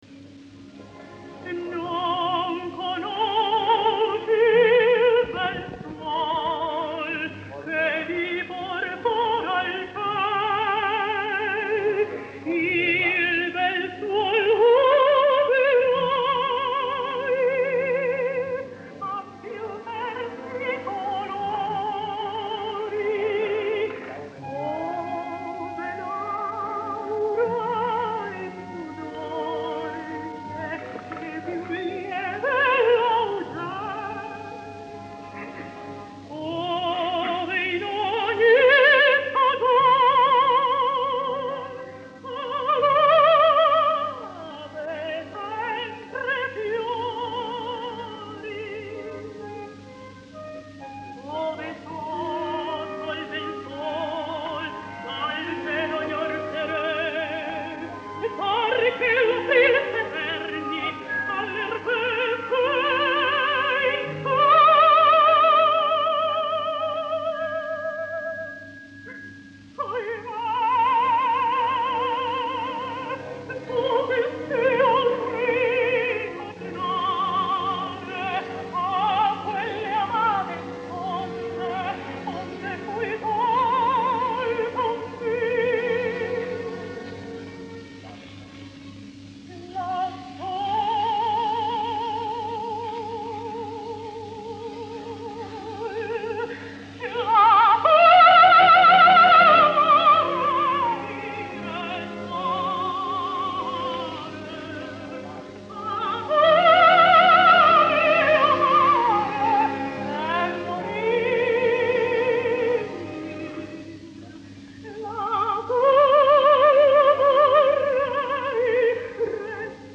当时观众反映热烈，她只好接着又唱一遍。